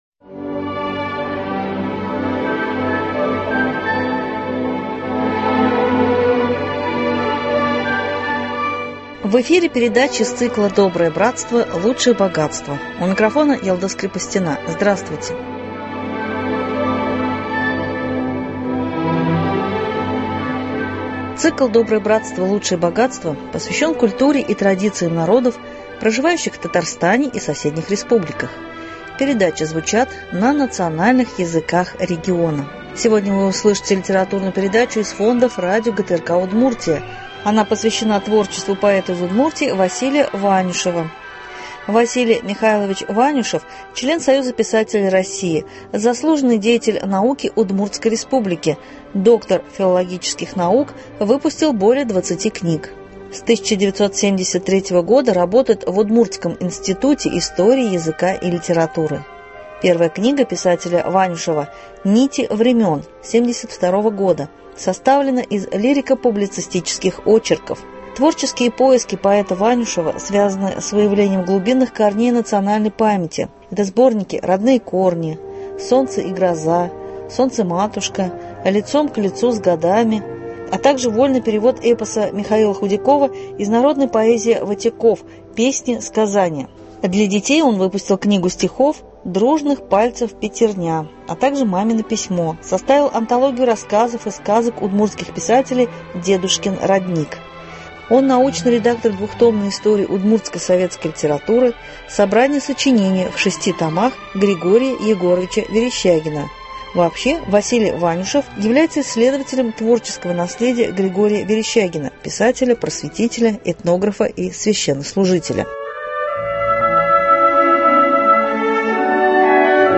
Сегодня вы услышите литературную передачу из фонда радио ГТРК Удмуртия. Она посвящена творчеству поэта из Удмуртии Василия Ванюшева.